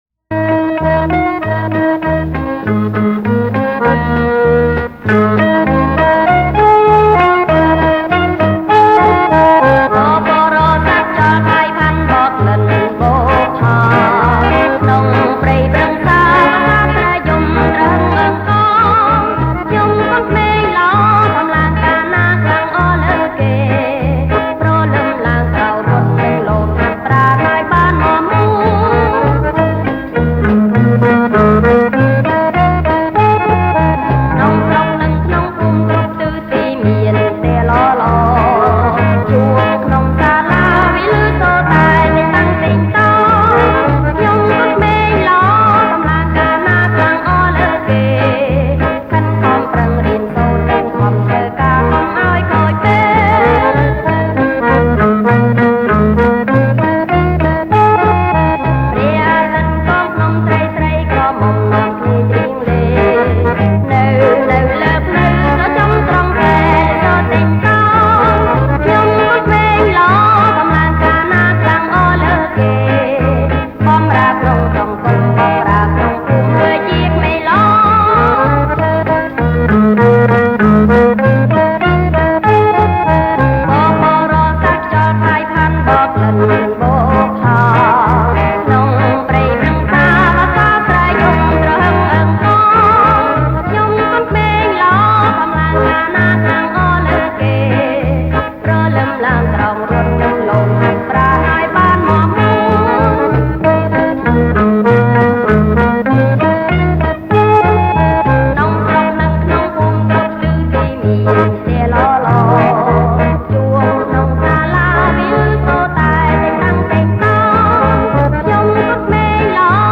• ប្រគំជាចង្វាក់ Fox medium